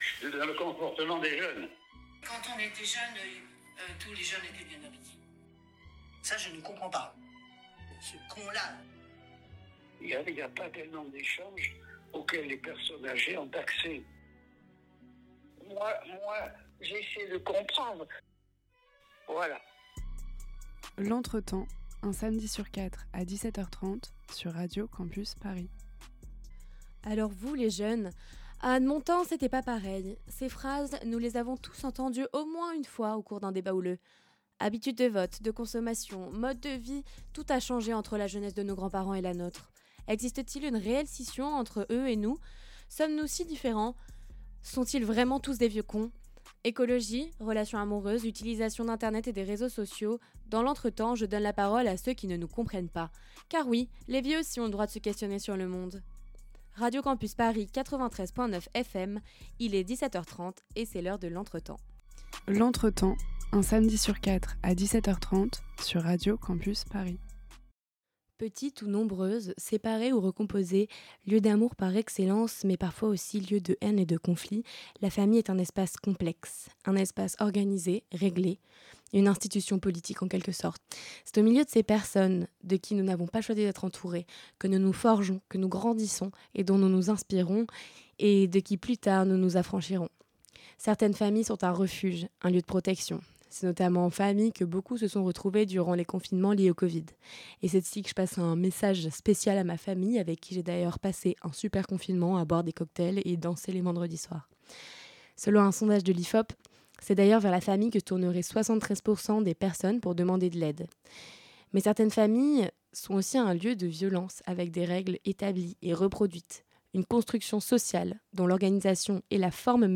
Partager Type Entretien Société samedi 17 mai 2025 Lire Pause Télécharger Monsieur Macron nous l'a dit, il faut un réarmement démographique pour la France........